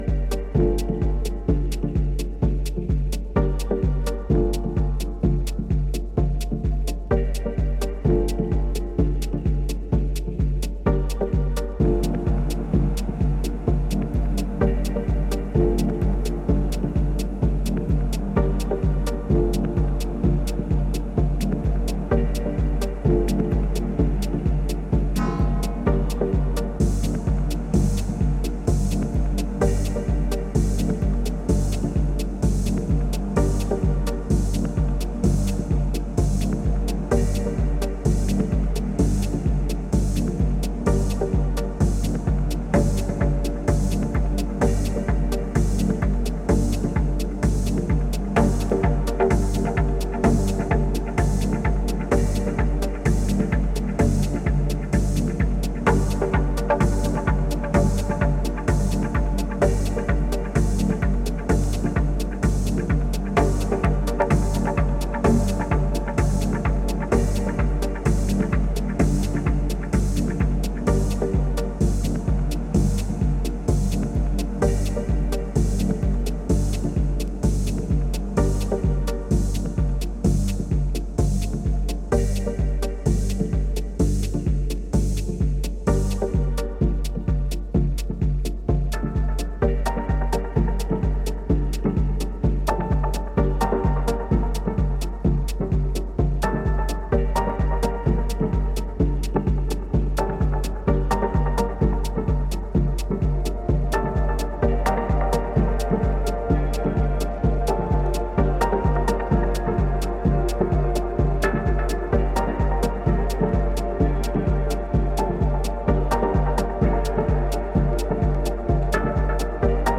Dub Techno Techno